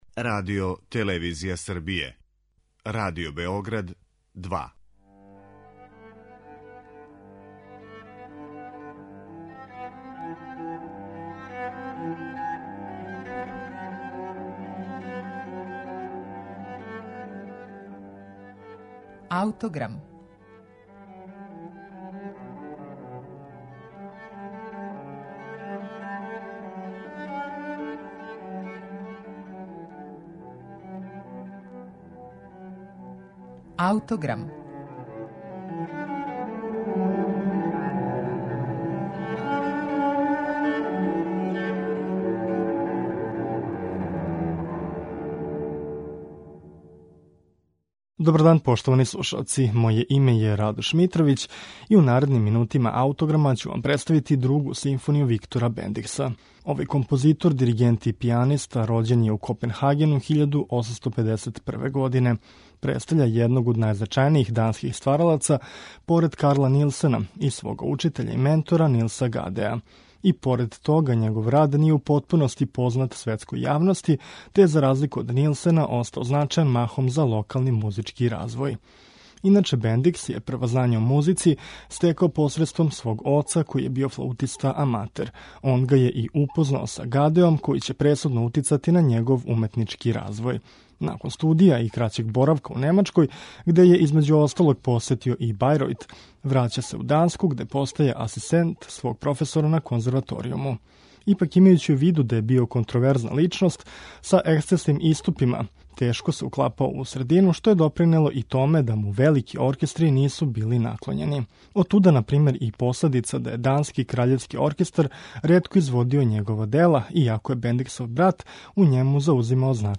реферира на ову земљу,а ми ћемо је слушати у извођењу оркестра Филхармоније из Омска